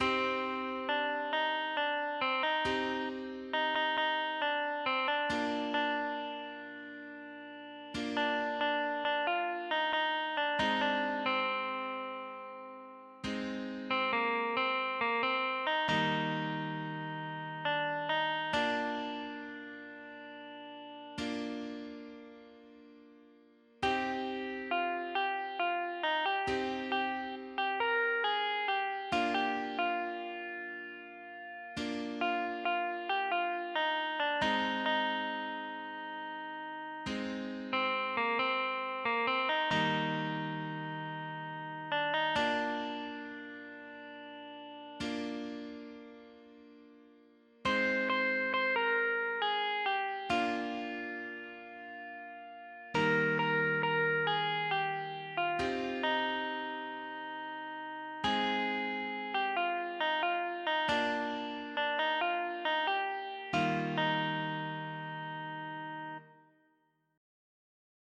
(Via Crucis cantado)